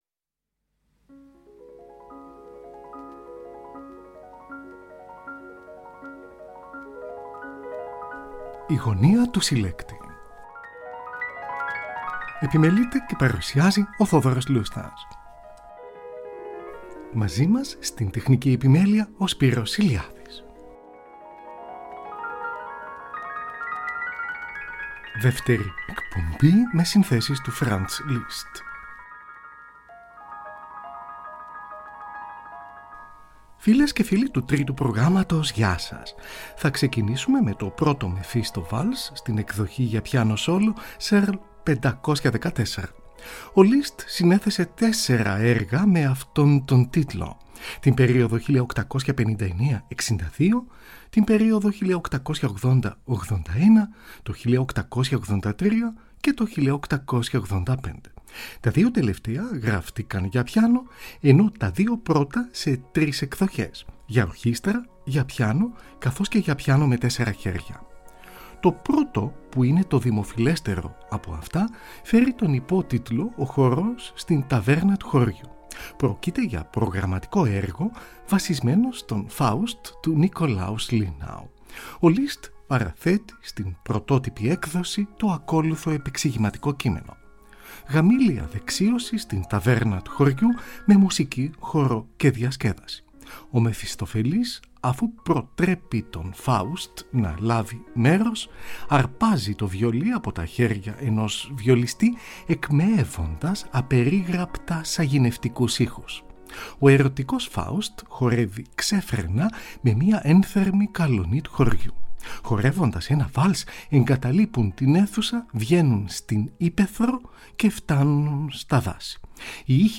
Παίζει ο πιανίστας Grigory Ginzburg, από ζωντανή ηχογράφηση στη Μεγάλη Αίθουσα του Ωδείου της Μόσχας. 23 Οκτωβρίου 1952.